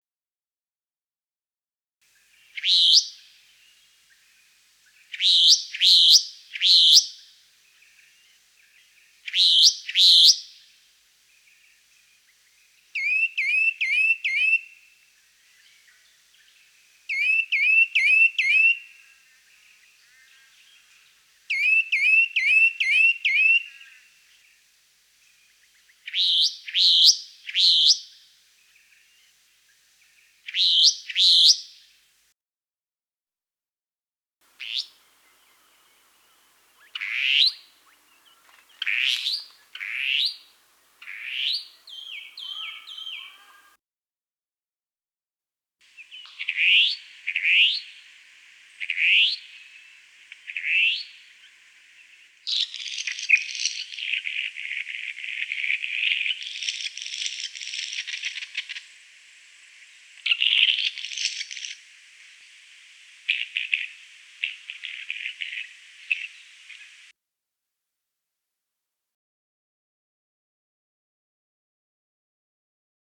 Unique Australian Bird Sounds
restless flycatcher
62-restless-flycatcher.mp3